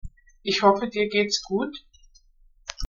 gut-aufsteigend.mp3